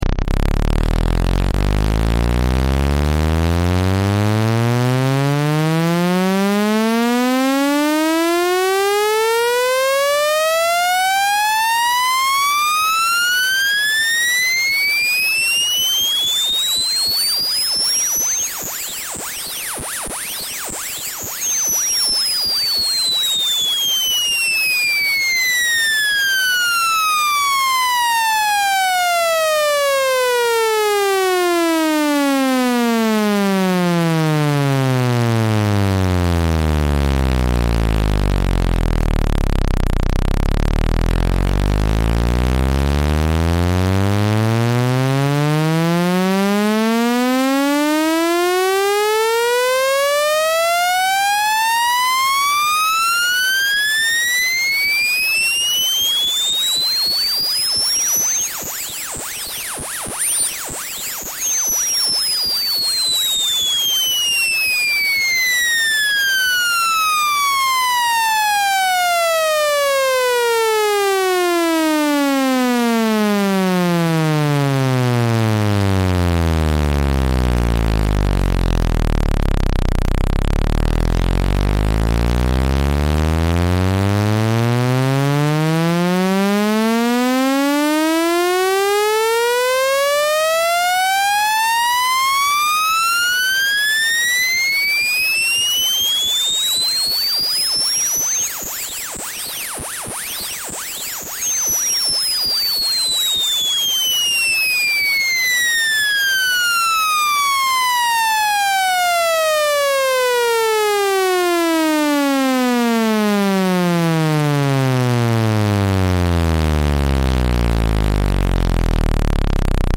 speaker and sound cleaner sound effects free download